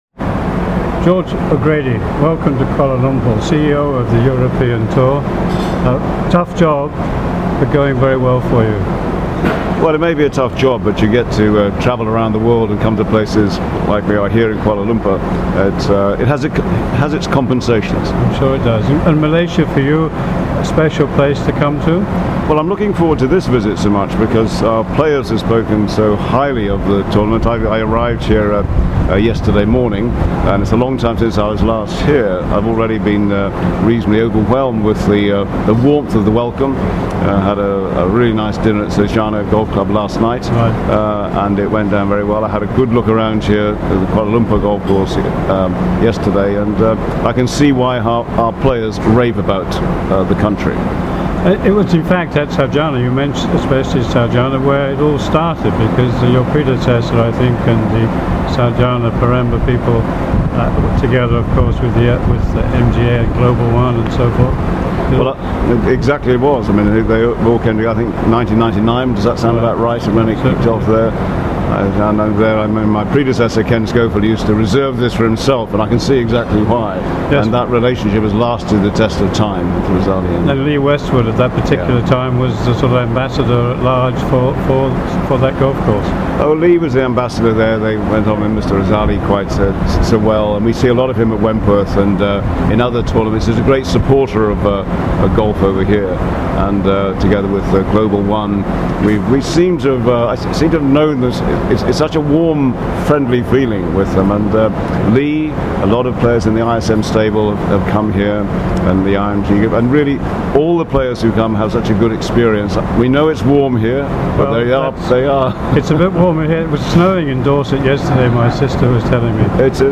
MGTA interviews George O'Grady